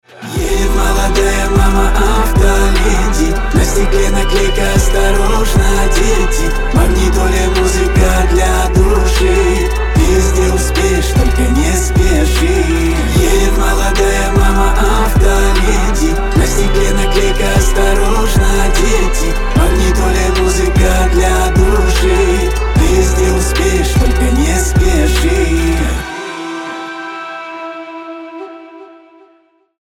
• Качество: 320, Stereo
мужской голос
спокойные